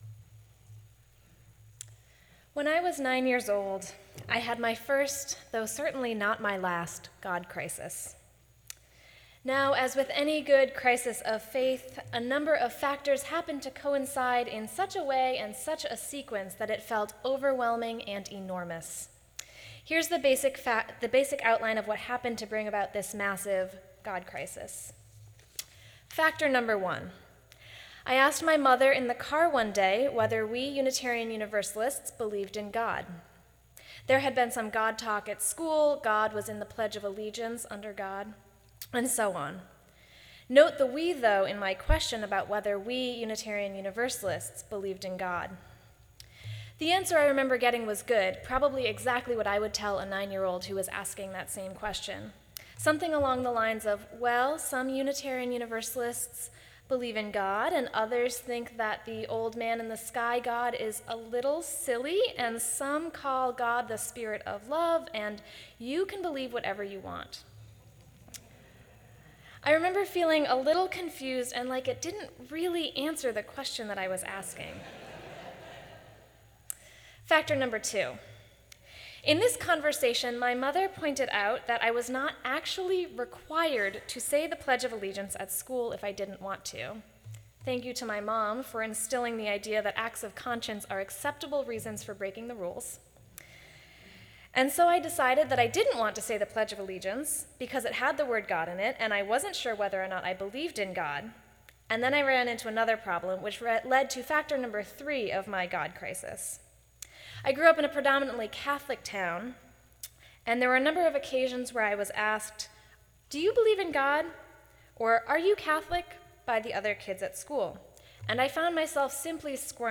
The podcast is of the reading and sermon.
Sermon9-28-14.mp3